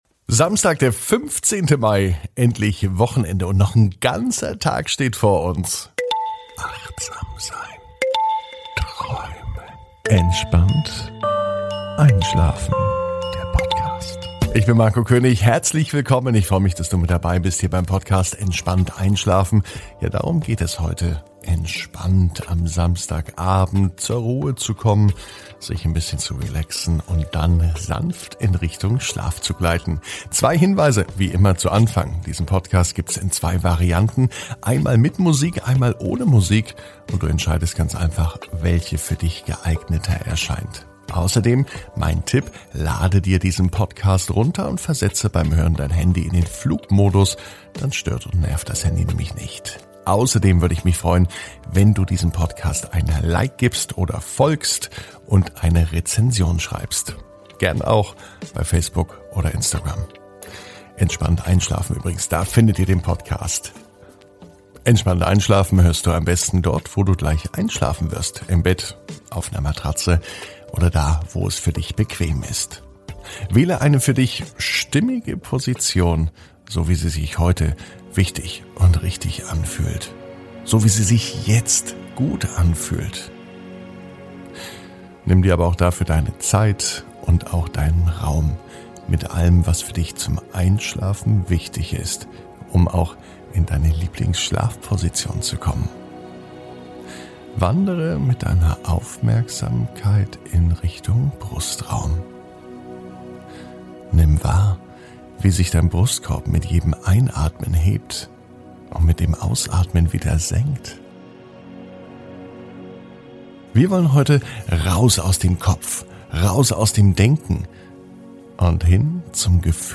Heute startet der neue Einschlafpodcast Entspannt einschlafen.